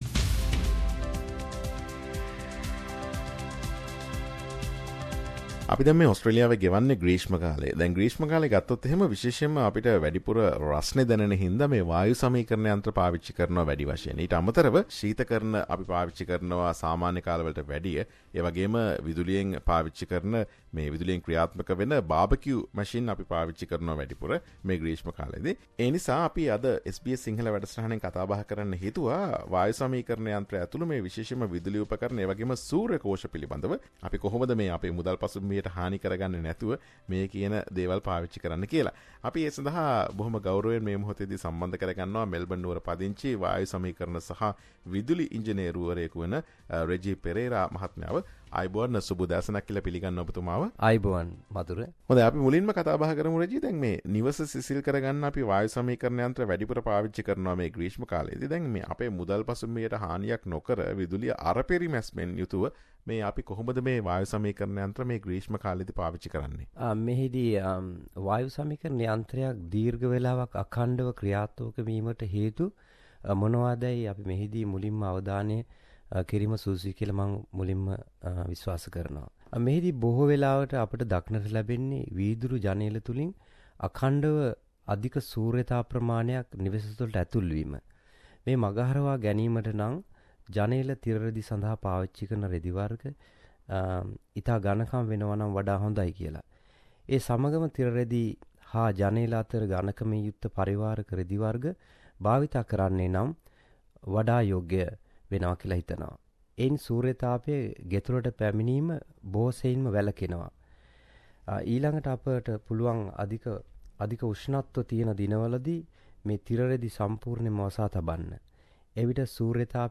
SBS සිංහල වැඩසටහන ගෙන එන සාකච්ඡාව.